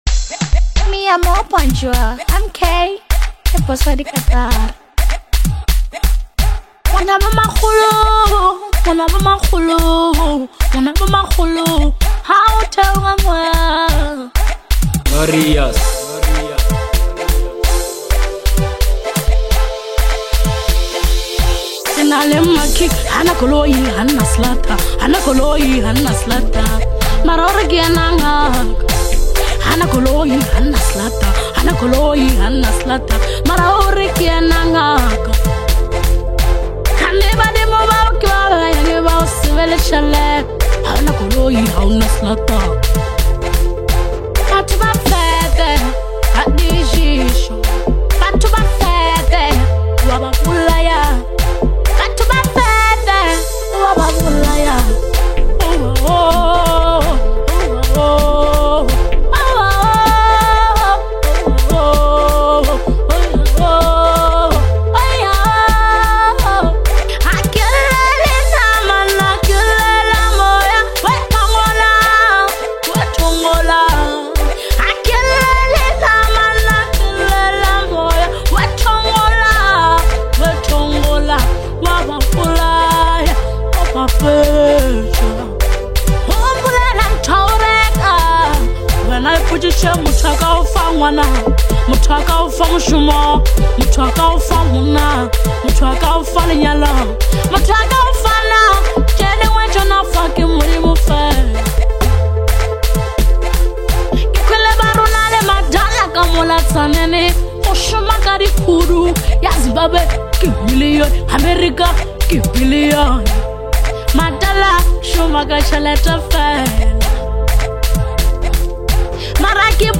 deeply moving Afro-house collaboration